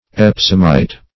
Epsomite \Ep"som*ite\, n. Native sulphate of magnesia or Epsom salt.